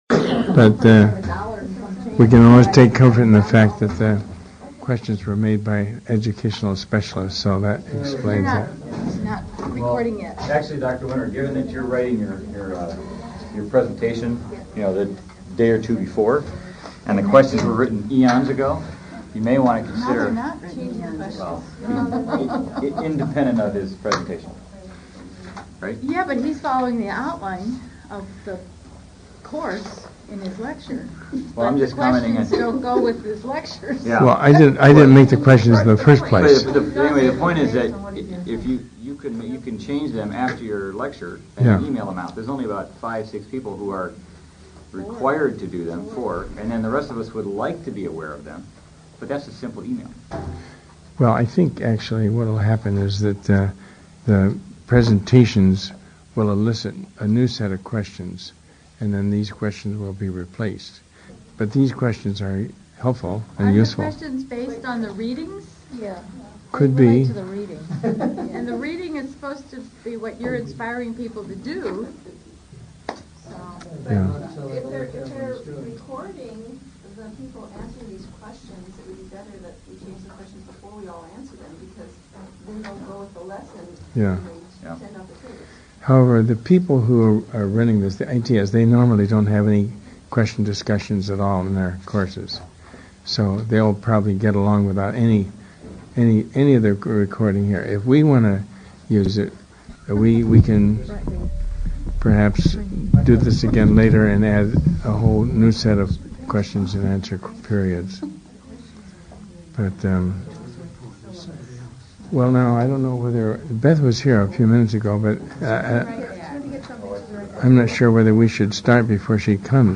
Lesson 3 Lecture: The Biblical Plan, Announcement of the Great Commission
lesson3-discussion.mp3